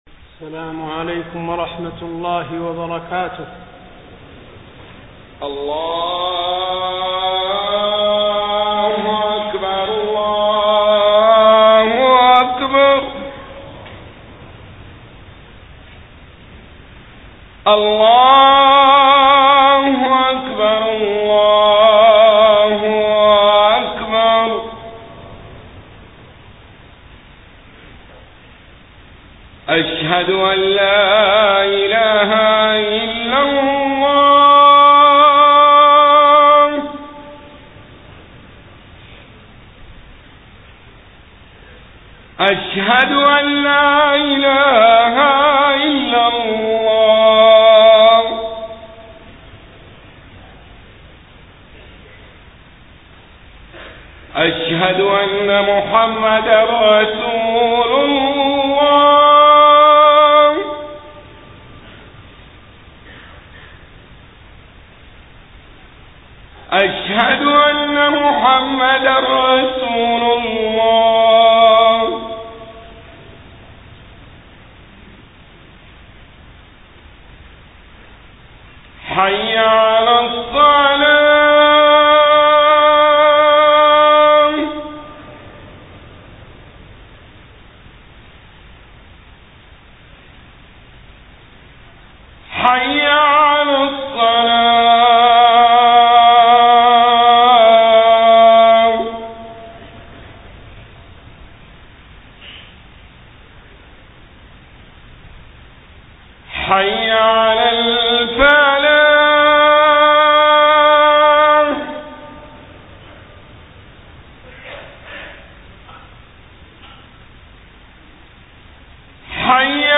خطبة الجمعة 5 ربيع الأول 1431هـ > خطب الحرم النبوي عام 1431 🕌 > خطب الحرم النبوي 🕌 > المزيد - تلاوات الحرمين